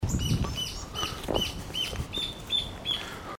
Rufous Hornero (Furnarius rufus)
Life Stage: Adult
Location or protected area: Reserva Ecológica Costanera Sur (RECS)
Condition: Wild
Certainty: Recorded vocal
hornero.mp3